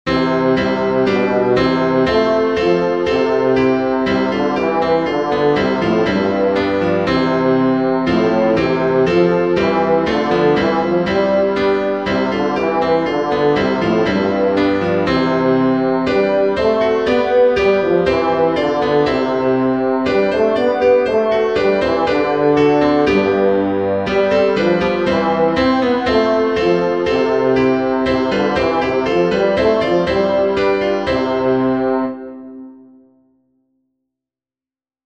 Bass
easter_jesus_christ_is_risen_today-bass.mp3